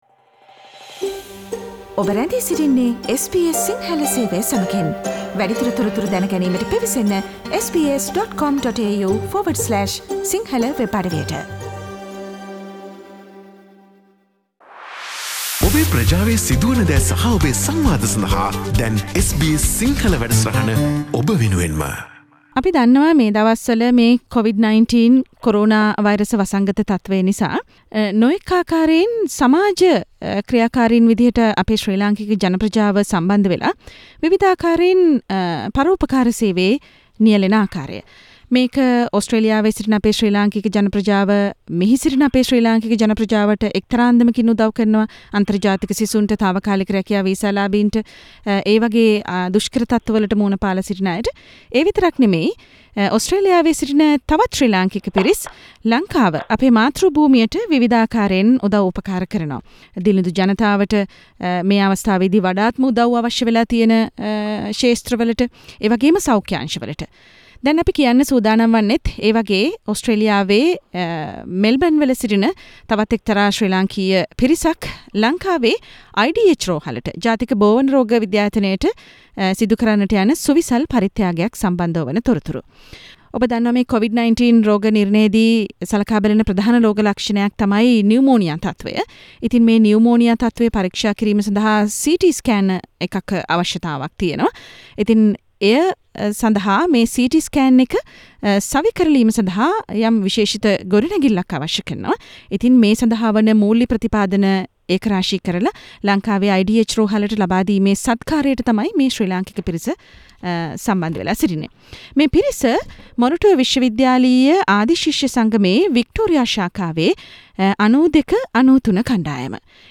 speaks to the SBS Sinhala radio